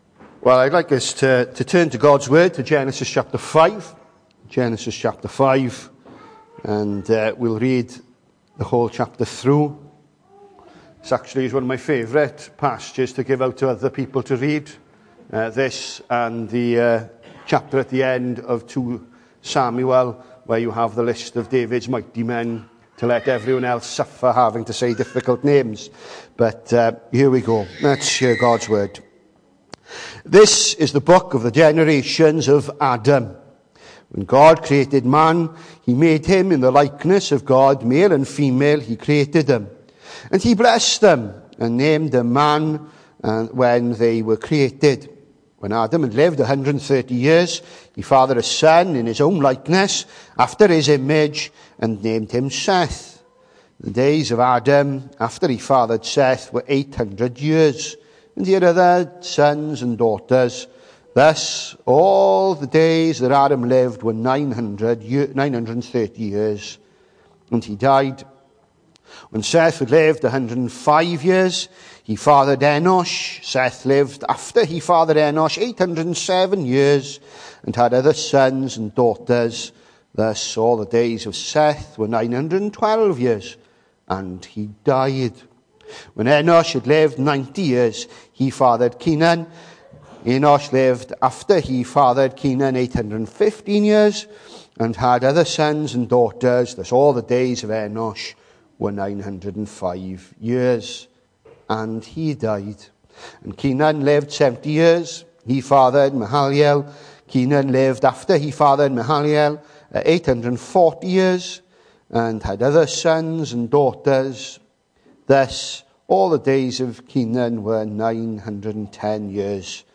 The 23rd of November saw us host our Sunday morning service from the church building, with a livestream available via Facebook.